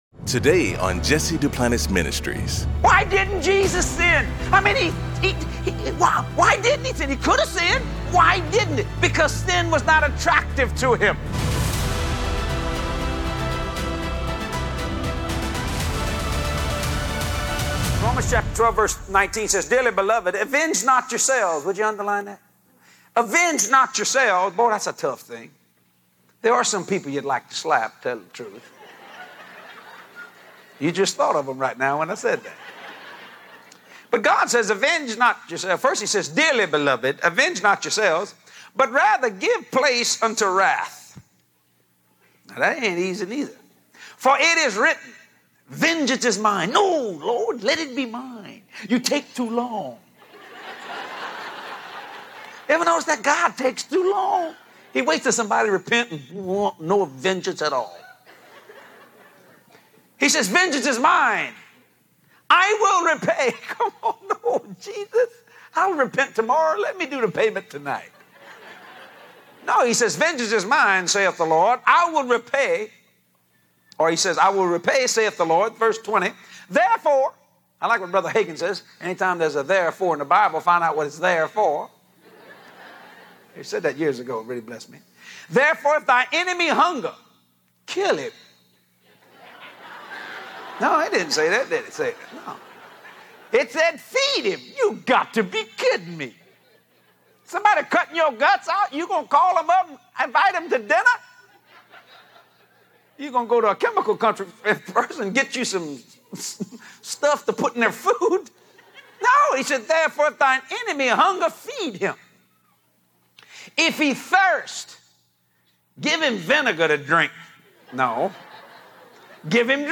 In this high-energy classic message